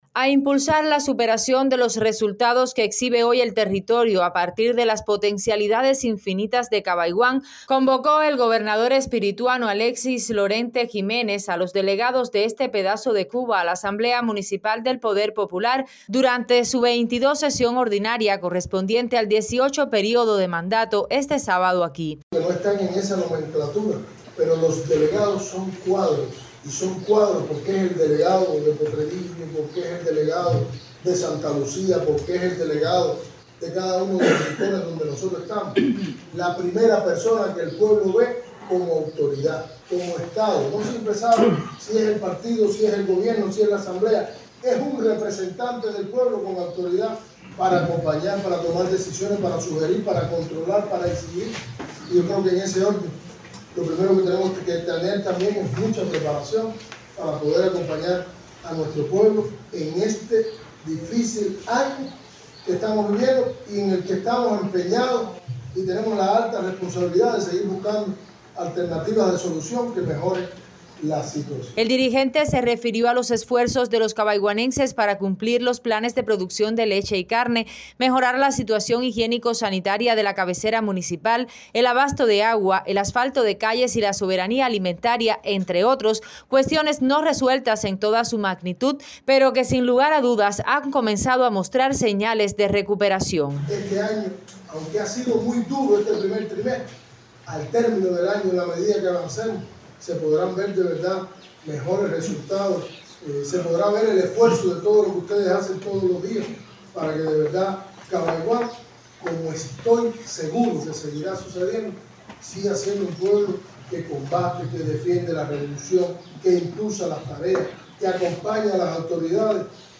A impulsar la superación de los resultados que exhibe hoy el territorio a partir de las potencialidades infinitas de Cabaiguán, convocó el Gobernador espirituano Alexis Lorente Jiménez a los delegados de este pedazo de Cuba a la Asamblea Municipal del Poder Popular, durante su 22 sesión ordinaria correspondiente al dieciocho período de mandato.